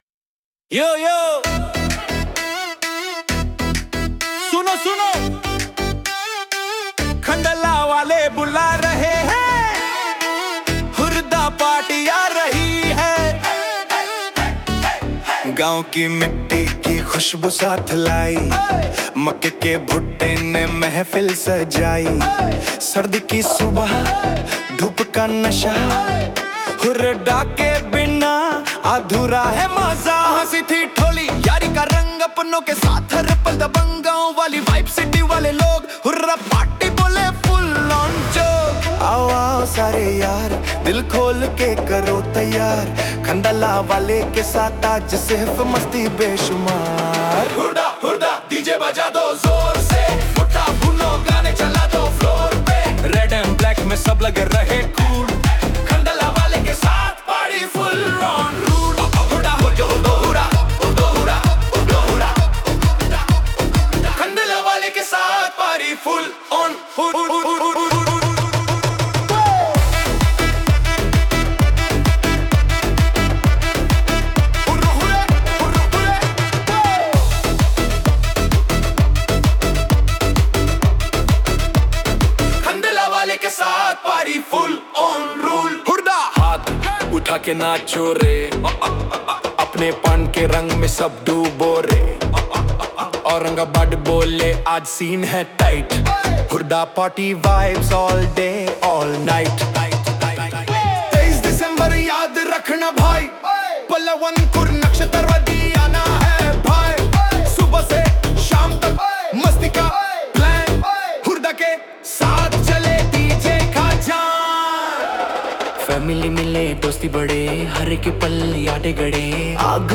• 🎧 Realistic AI Voice (Male / Female / Duet) Access